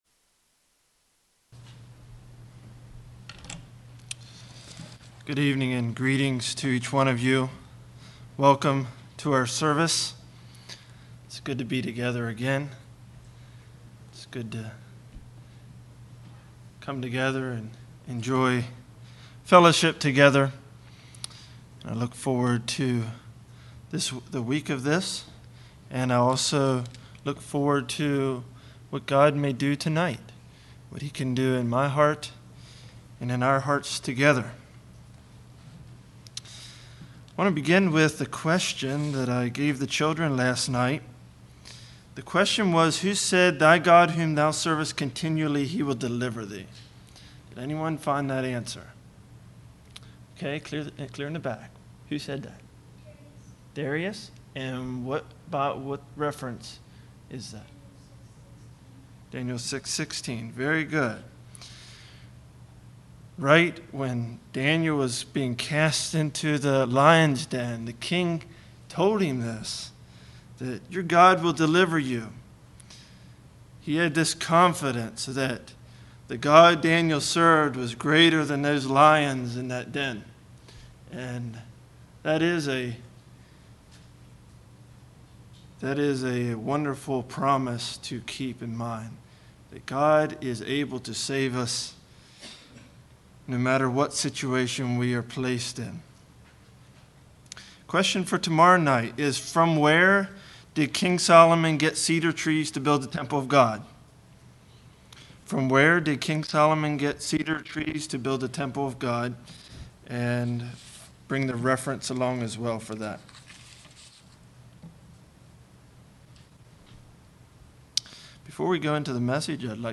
2015 Sermon ID